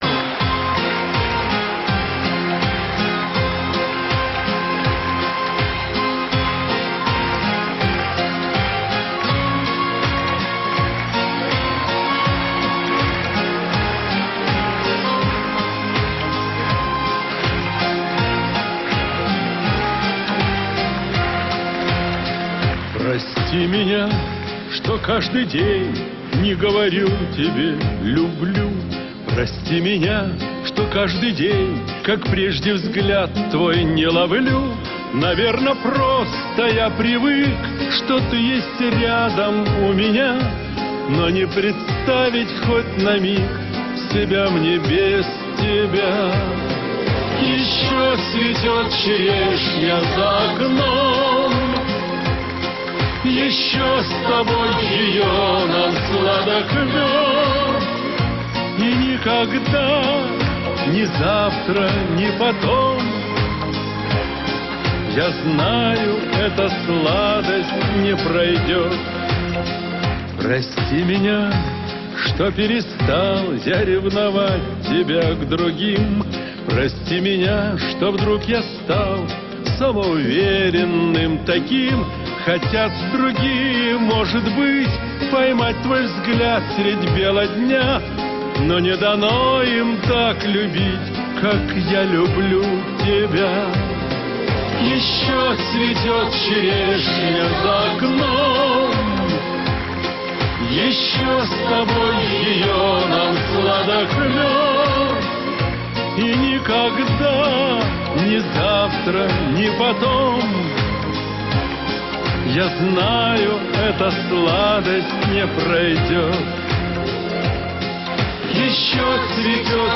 Т.е. твоя запись из этого видео -SATRip.